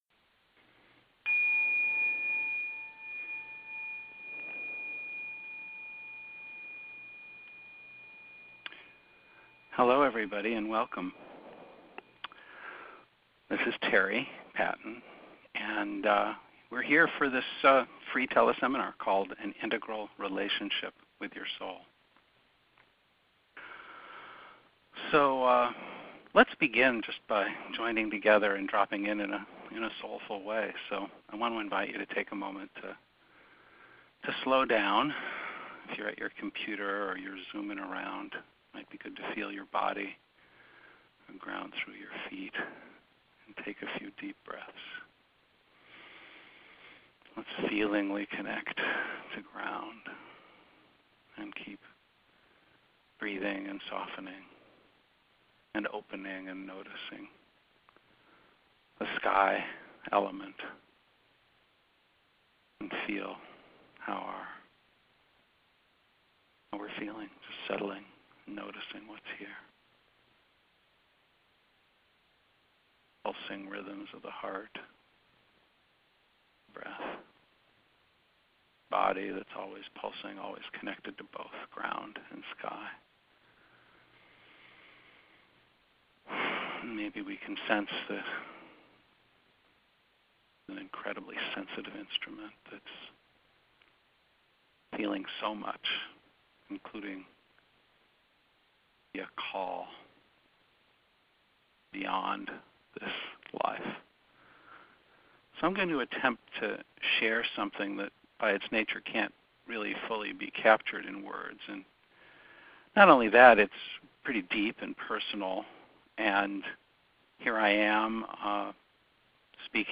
Teleseminar: An Integral Relationship with Your Soul